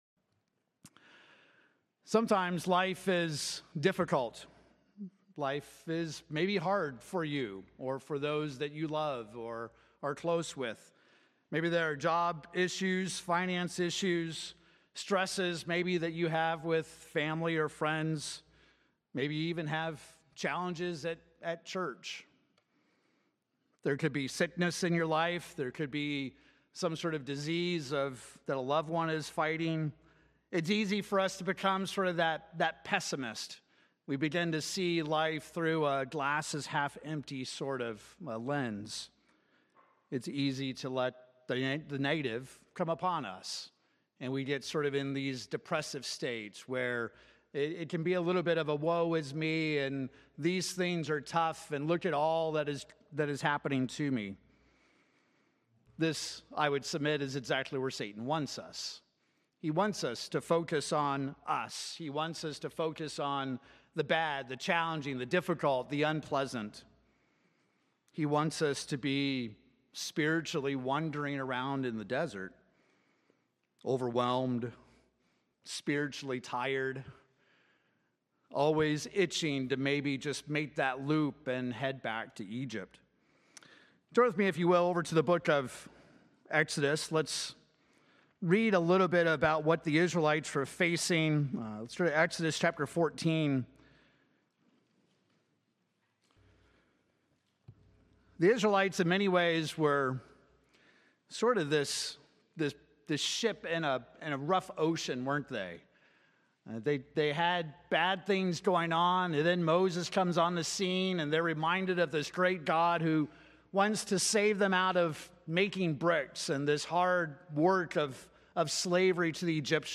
Sermons
Given in Oklahoma City, OK Tulsa, OK